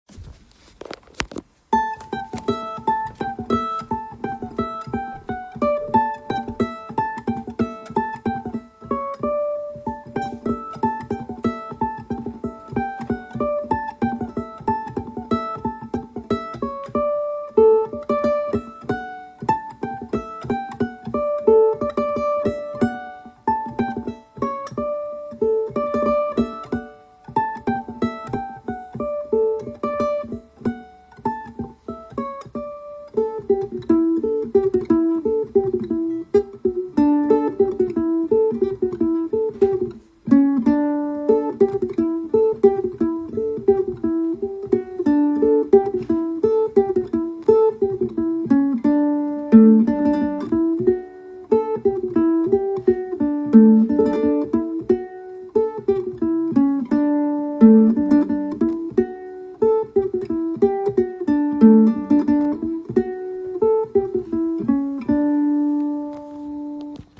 Key : D Form
Genre/Style: Bourrée